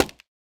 Minecraft Version Minecraft Version 1.21.5 Latest Release | Latest Snapshot 1.21.5 / assets / minecraft / sounds / block / scaffold / place4.ogg Compare With Compare With Latest Release | Latest Snapshot